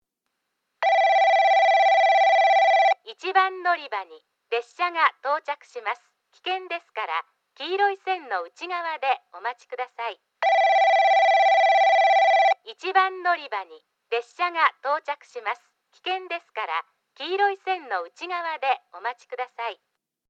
1番のりば　接近放送　女声
スピーカーはTOA防滴型、TOAラッパ型でした。